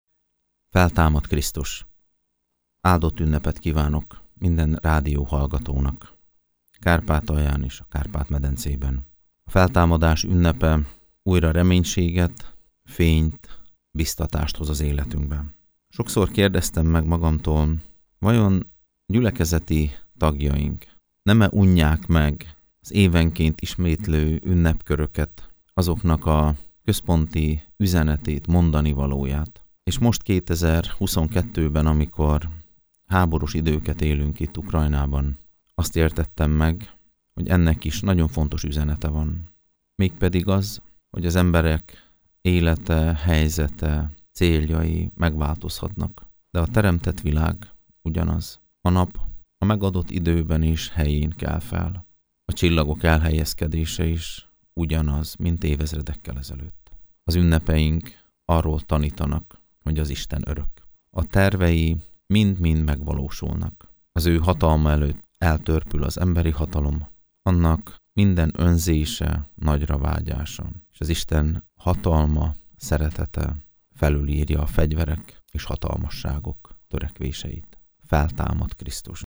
Zán Fábián Sándor, a Kárpátaljai Református Egyházkerület püspökének húsvéti köszöntőjét hallhatják